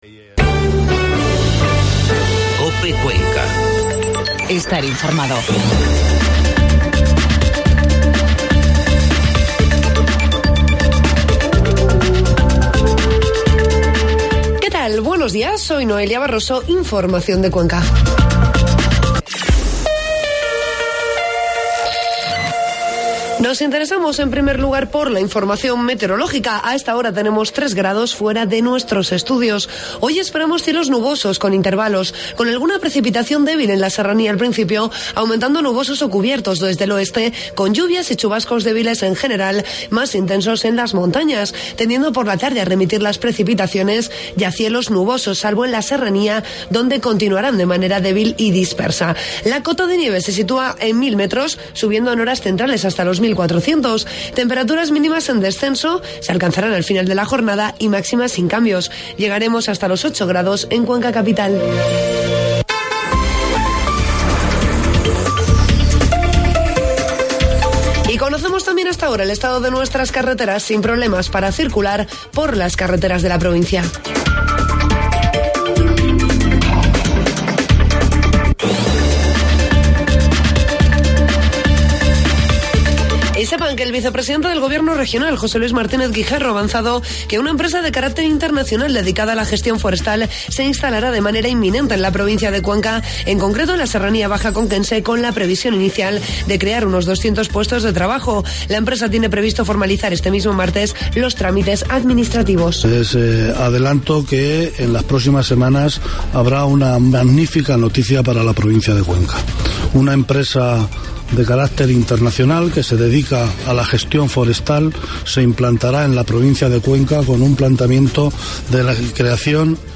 Informativo matinal COPE Cuenca 6 de marzo